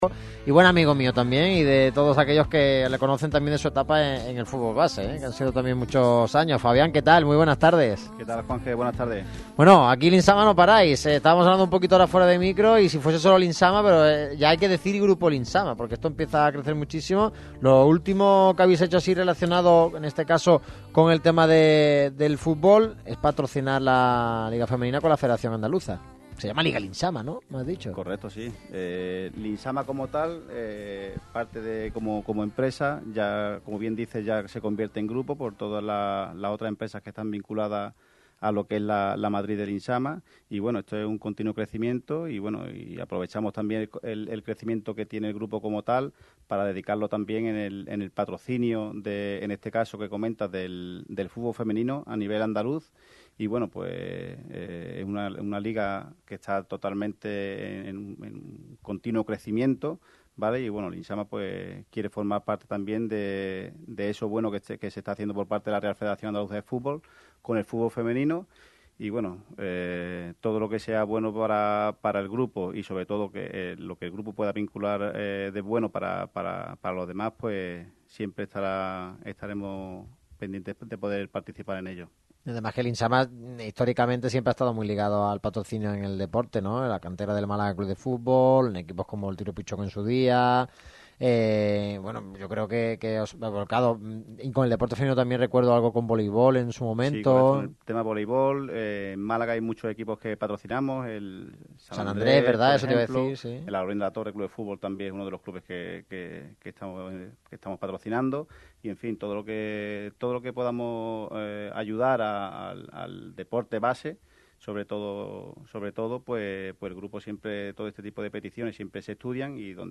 La radio que vive el deporte se desplazó este miércoles 15 de septiembre hasta las instalaciones de Grupo Limsama. Todo ello para emitir su habitual programa. En él se dieron todos los detalles de la actualidad del deporte malagueño.